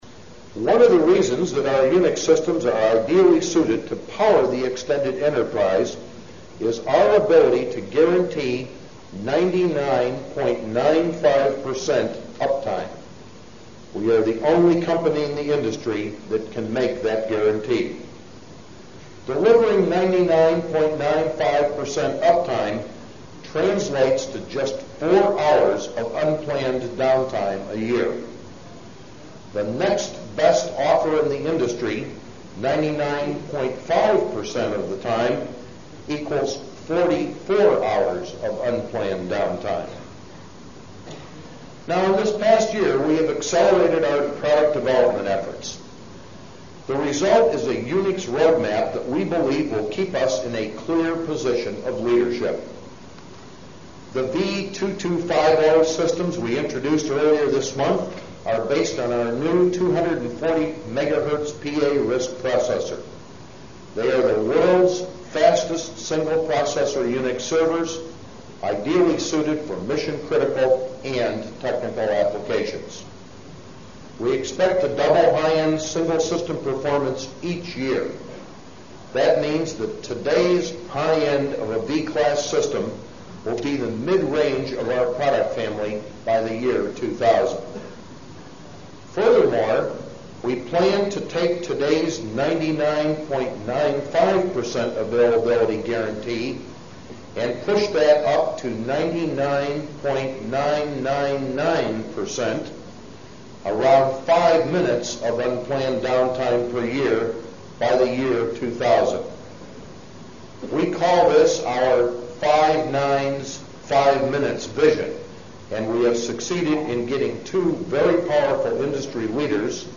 财富精英励志演讲 第160期:携手打造电子化世界(5) 听力文件下载—在线英语听力室